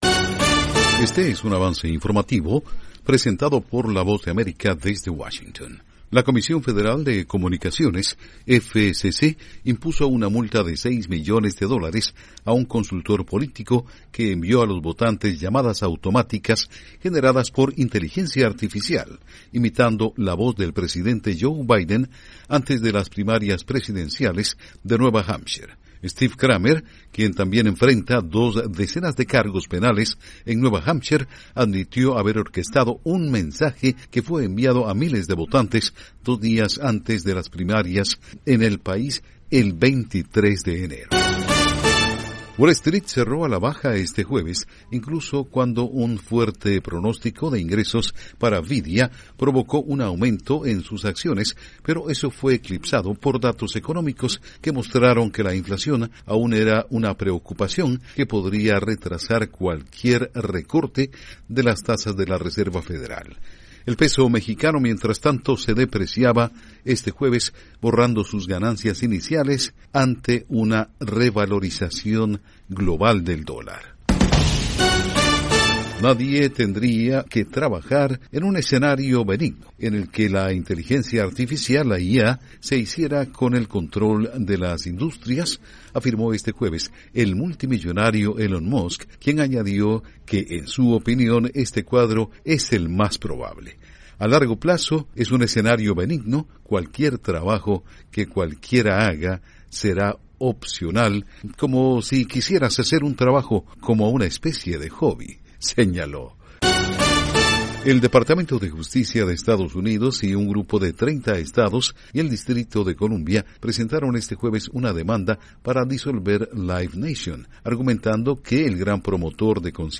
Este es un avance informativo de la Voz de América.